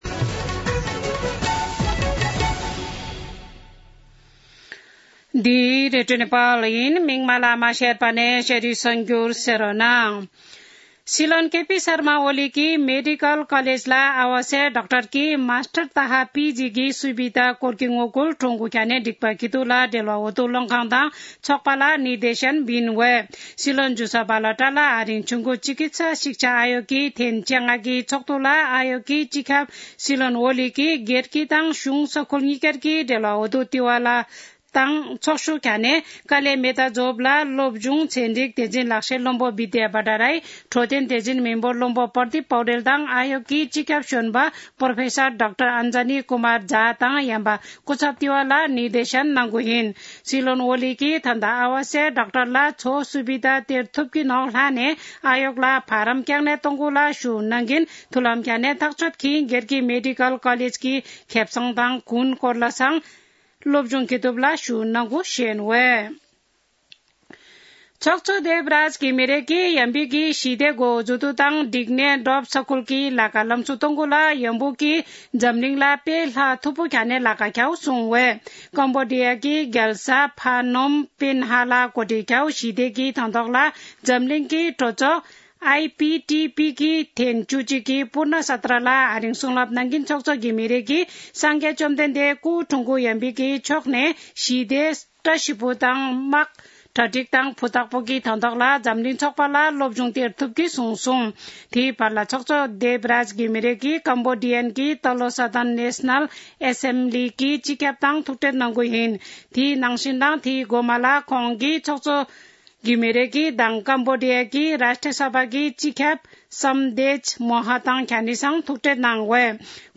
शेर्पा भाषाको समाचार : १० मंसिर , २०८१
4-pm-Sherpa-News.mp3